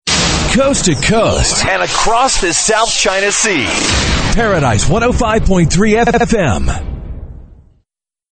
RADIO IMAGING / TOP 40